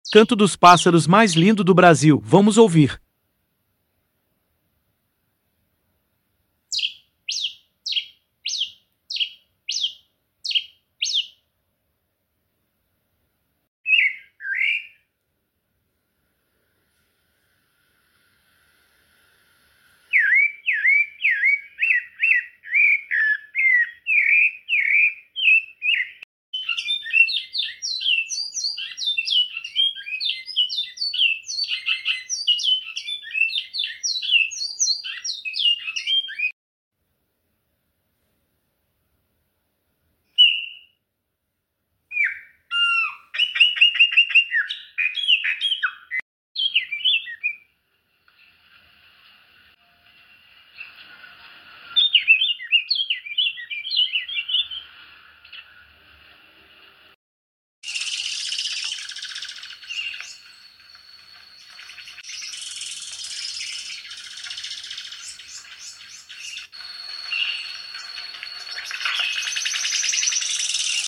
canto dos pássaros mais lindo sound effects free download